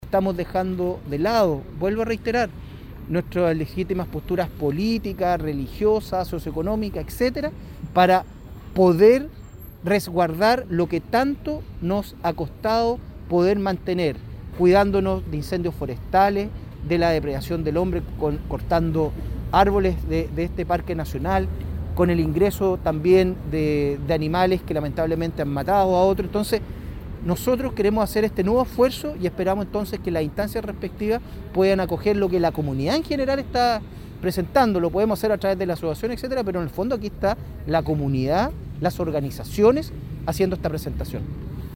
Una de las comunas más afectadas por este trazado sería Hualqui, situación en la que profundizó el alcalde Jorge Contanzo. La autoridad agregó que como municipio no descartan la presentación de acciones judiciales.